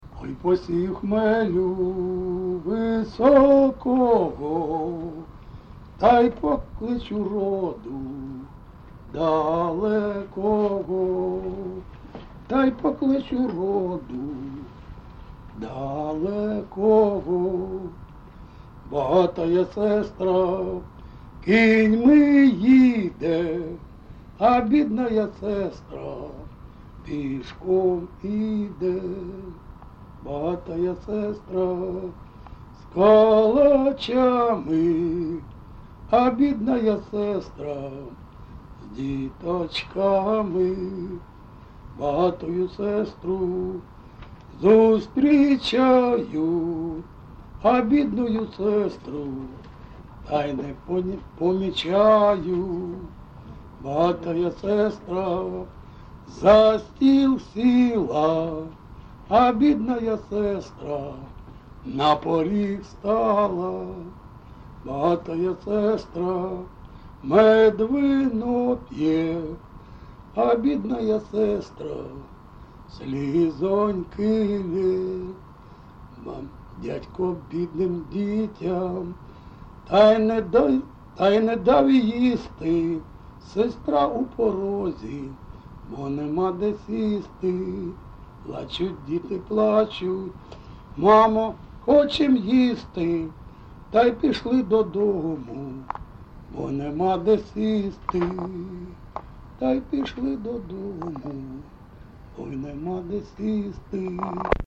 Місце записус-ще Красноріченське, Кремінський район, Луганська обл., Україна, Слобожанщина